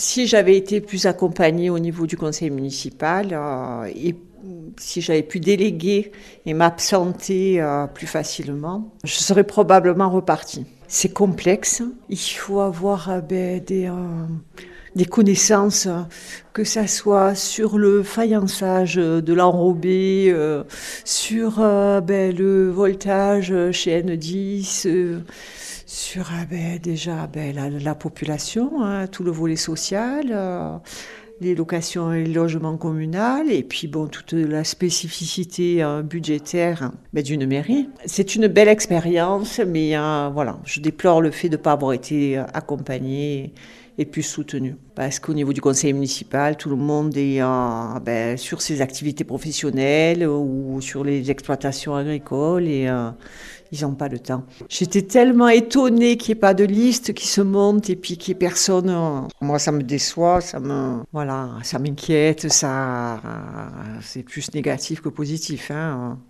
Frédérique Pélissier-Godard, la maire sortante, explique son choix de ne pas se représenter. L’élue, en fonction encore quelques semaines, évoque également ses craintes pour l’avenir de la commune.